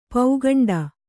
♪ paugaṇḍa